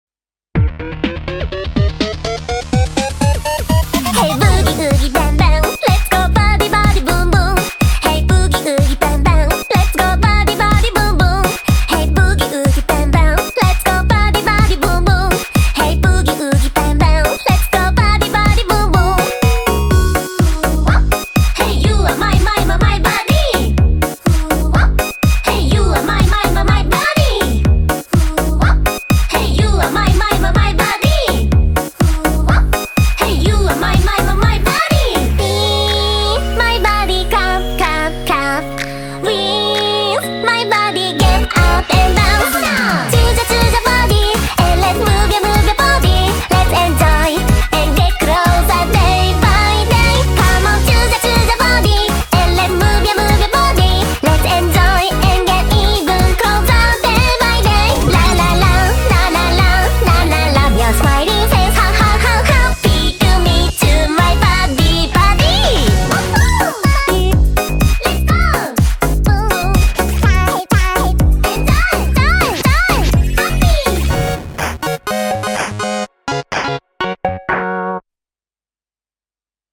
BPM124
Audio QualityCut From Video